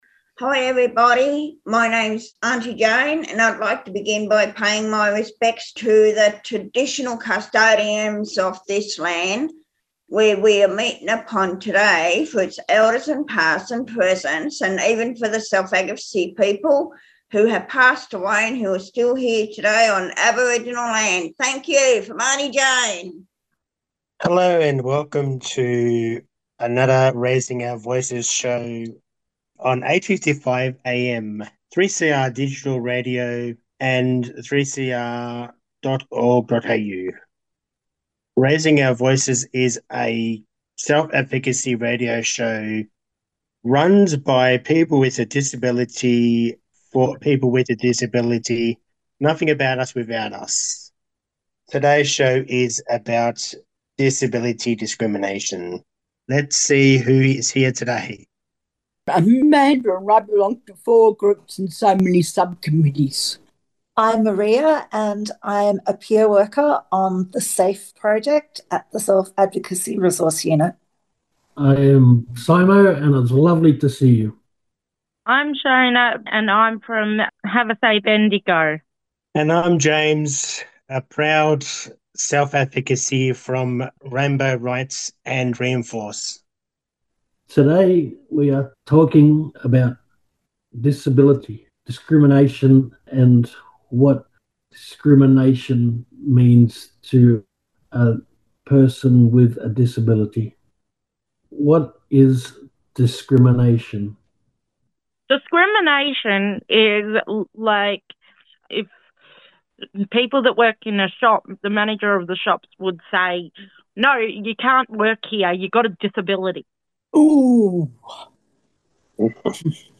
Produced and presented by people with disabilities